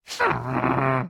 mob / witch / ambient3.ogg
ambient3.ogg